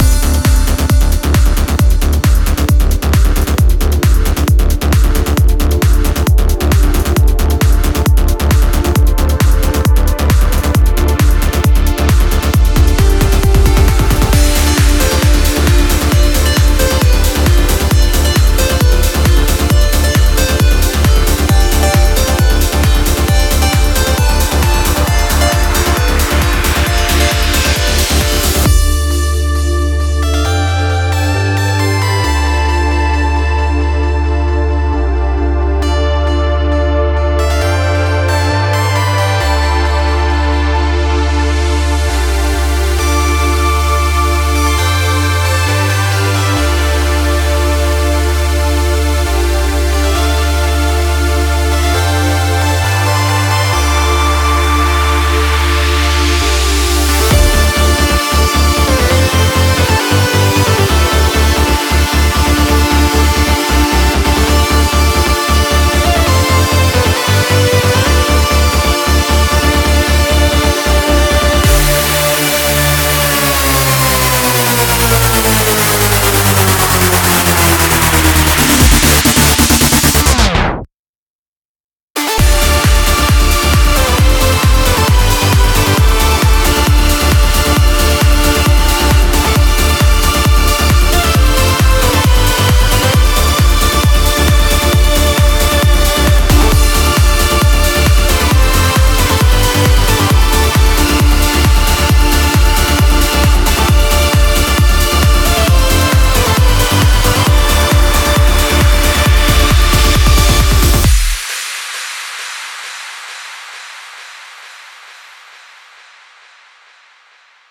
BPM34-134
Audio QualityPerfect (High Quality)
Comments[TRANCE ANTHEM]